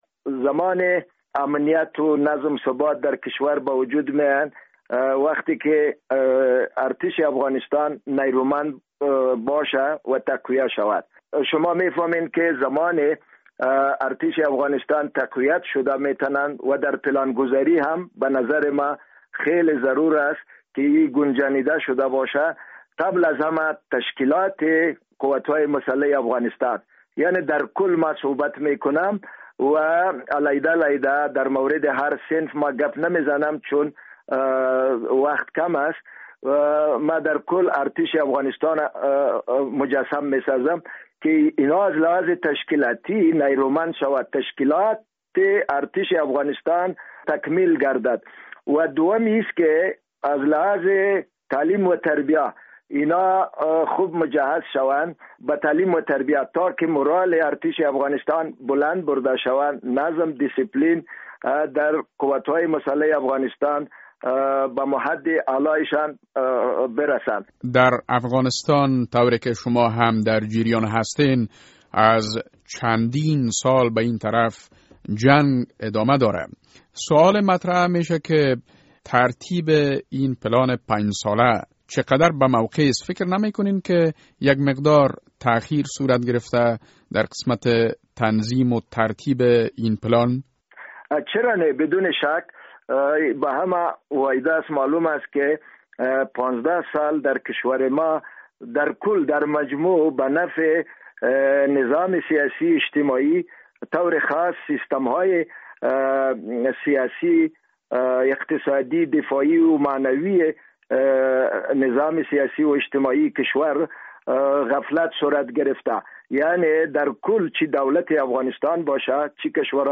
مصاحبه - صدا
شهنواز تنی، وزیر دفاع سابق افغانستان